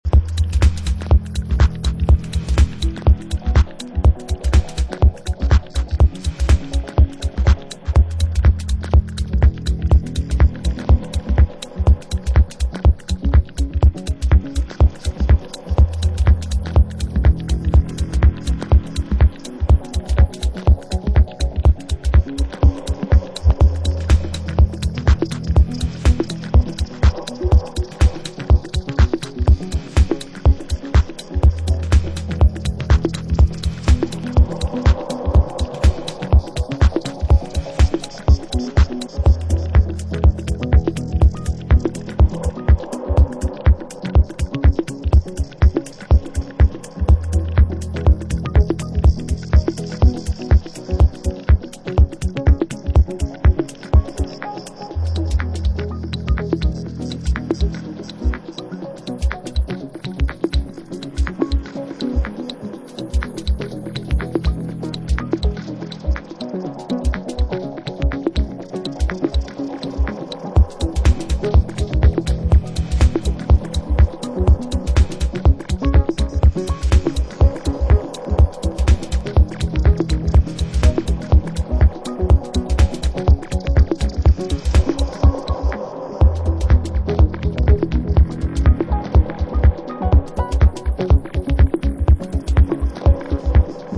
Excellent dubby techno- house
Techno